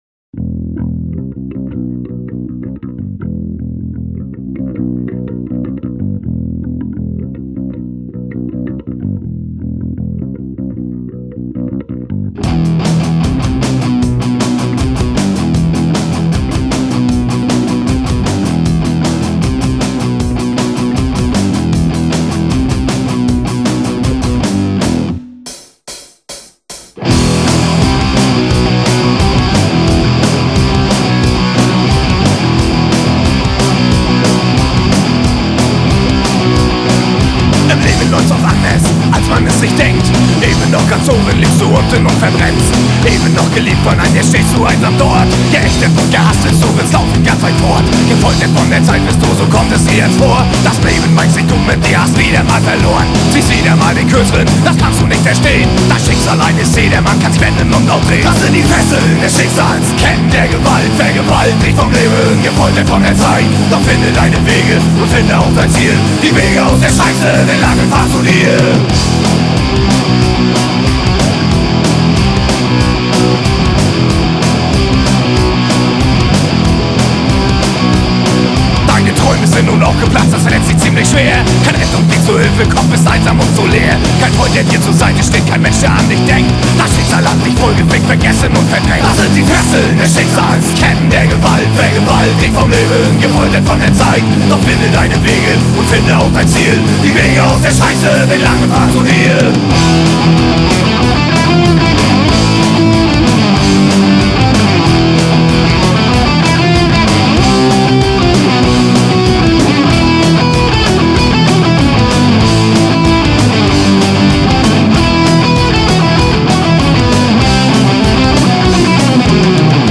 deutschsprachige Punk n`Roll Band
Bass
Gitarre